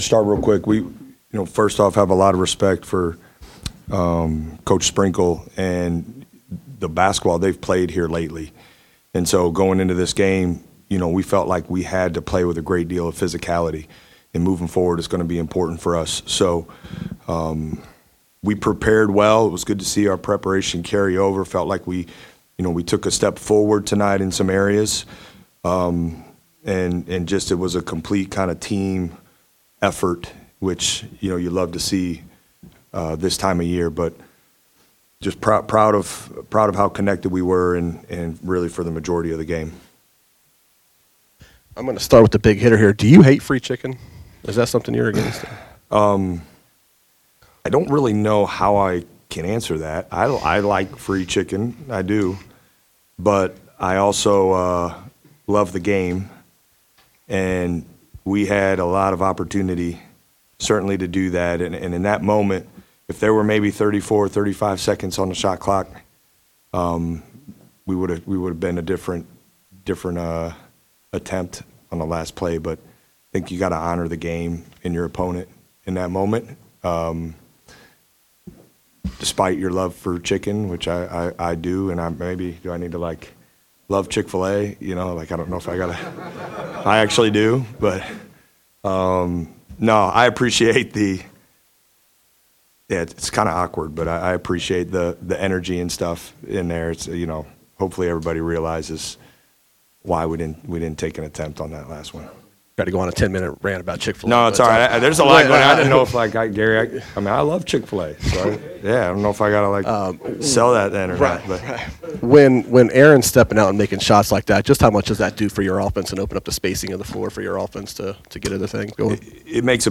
Jake Diebler Postgame Press Conference following Buckeyes 93-68 win over Washington